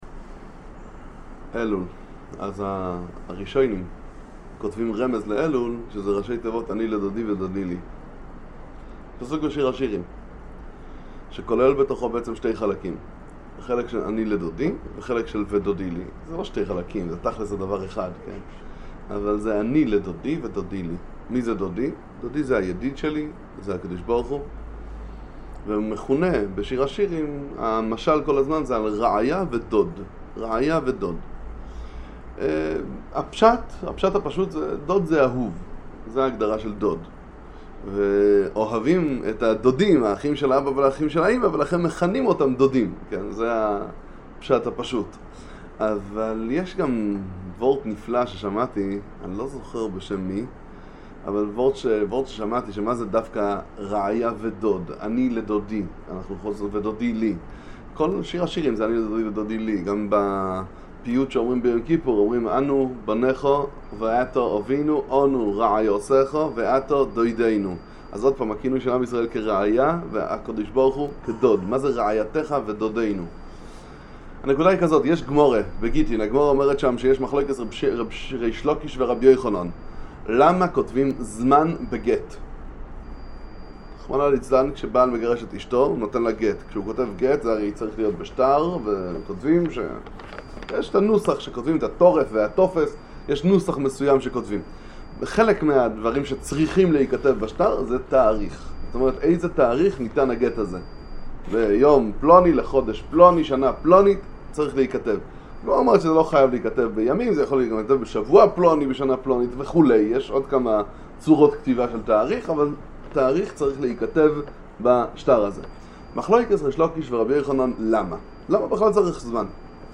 דברי חיזוק לחודש אלול, שיעורי תורה למועדי ישראל